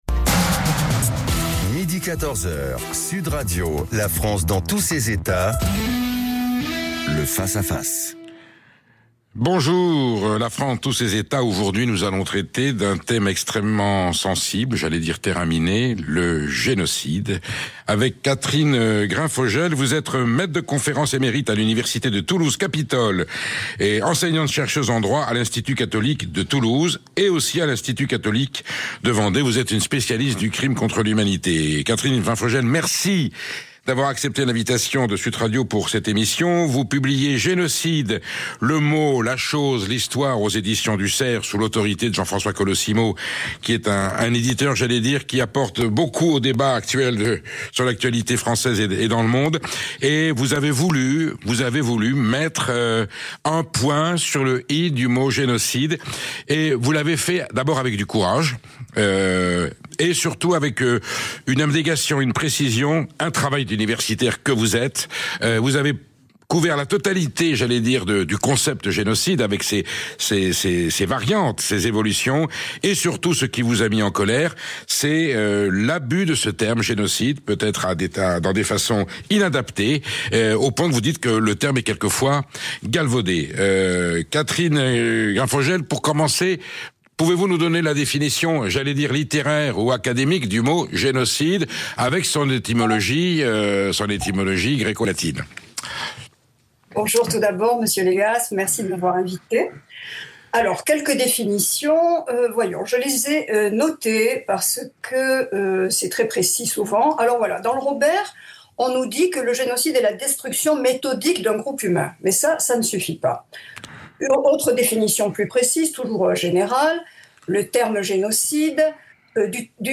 Interview en direct